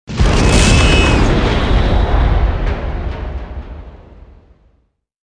med explo 3.wav